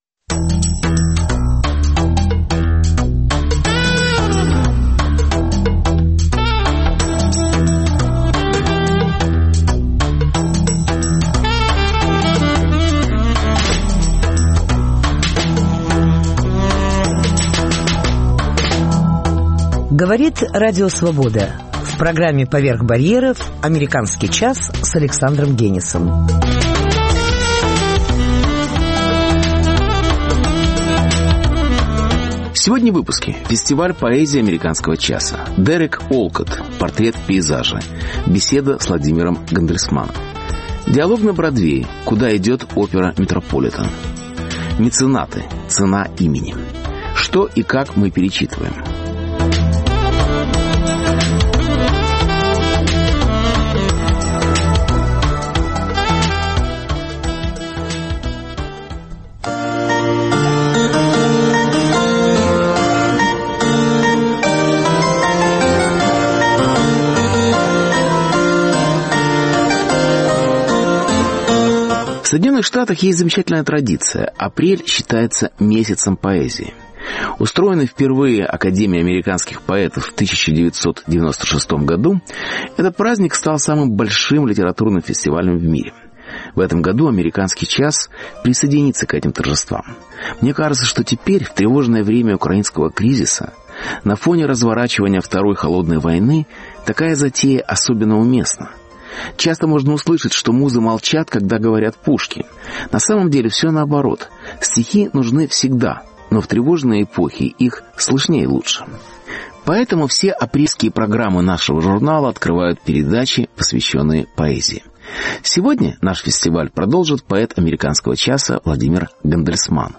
Фестиваль поэзии АЧ. Дерек Уолкотт: портрет в пейзаже. Беседа с Владимиром Гандельсманом